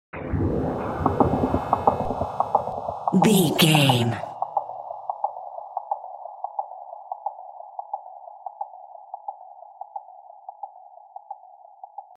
Lo Witch Stinger.
In-crescendo
Thriller
Atonal
scary
ominous
dark
suspense
eerie
horror
Horror Synths
Scary Piano
Scary Strings